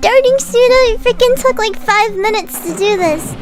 Worms speechbanks
Hurry.wav